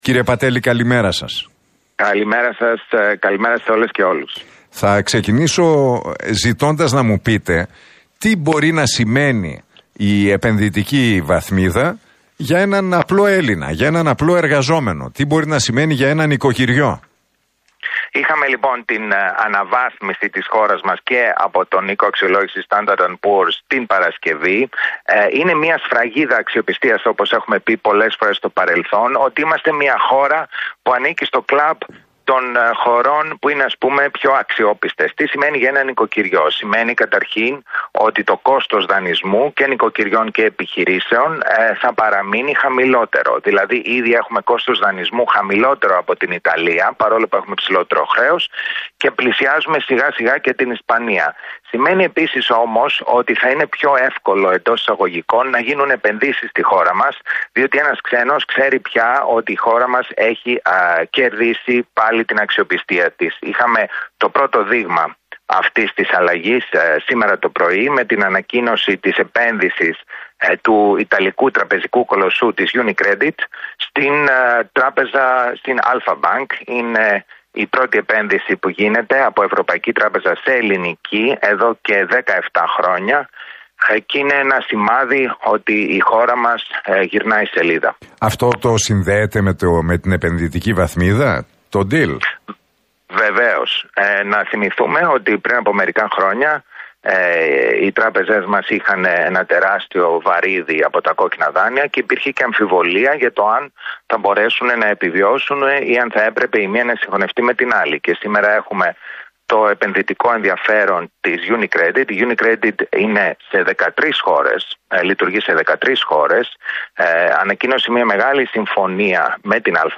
Για ένα νοικοκυριό ή μια επιχείρηση σημαίνει πως το κόστος δανεισμού θα παραμείνει χαμηλότερο, αλλά και ότι θα είναι πιο “εύκολο” να γίνουν επενδύσεις στη χώρα μας» είπε ο Αλέξης Πατέλης μιλώντας στον Realfm 97,8 και την εκπομπή του Νίκου Χατζηνικολάου.